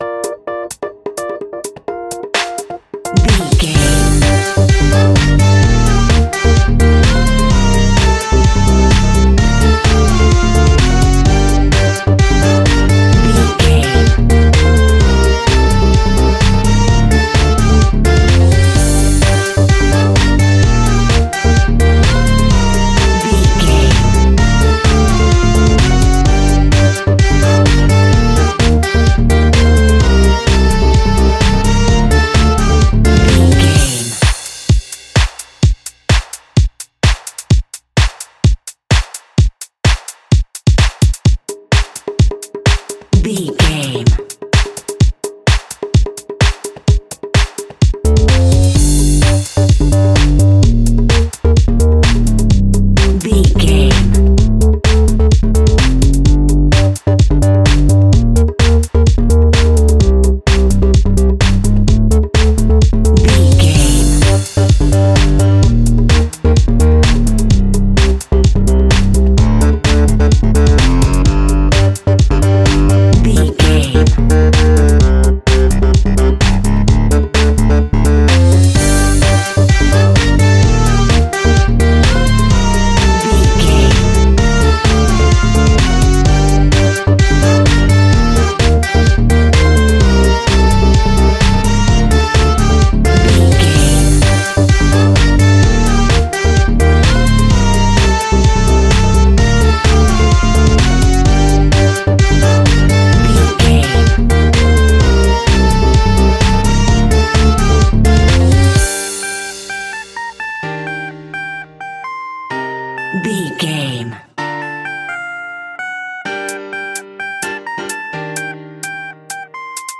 Aeolian/Minor
groovy
uplifting
driving
energetic
repetitive
brass
drum machine
synthesiser
electric piano
house
electro house
funky house
synth bass
guitar